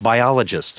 Prononciation du mot biologist en anglais (fichier audio)
Prononciation du mot : biologist